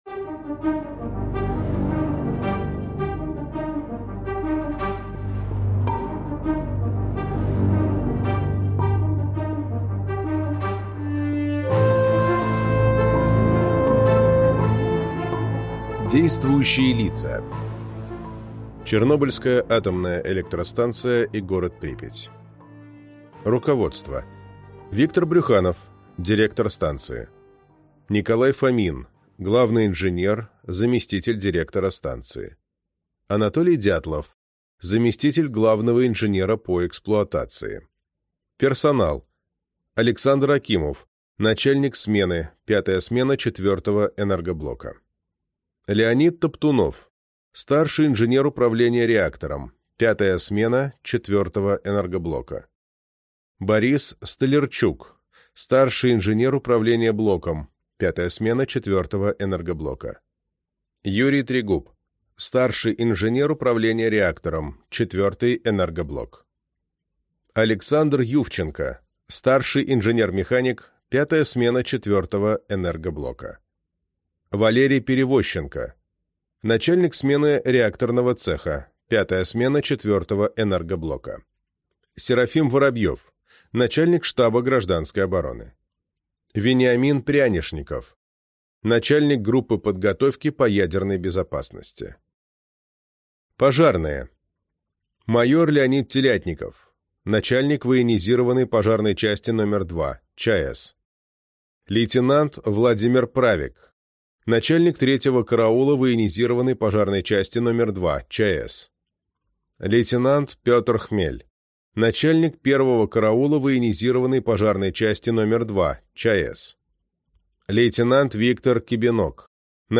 Аудиокнига Чернобыль. История катастрофы | Библиотека аудиокниг